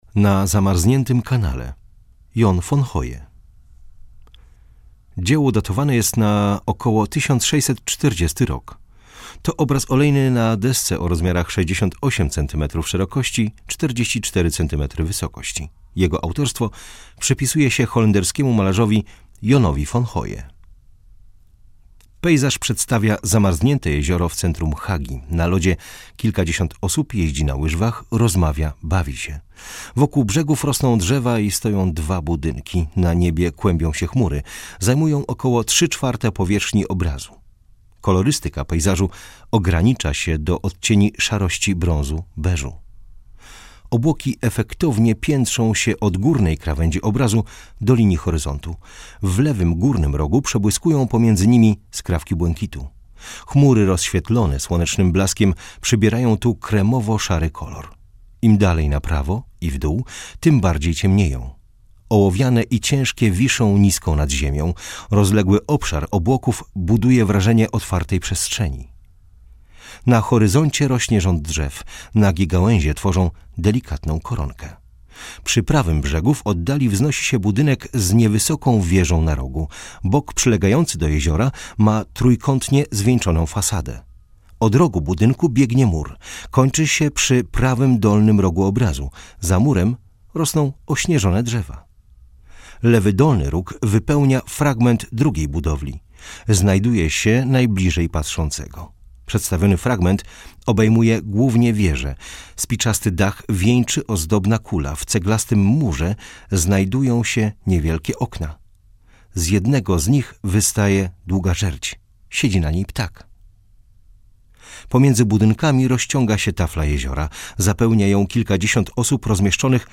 Audiodeskrypcja - EUROPEUM